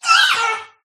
kubfu_ambient.ogg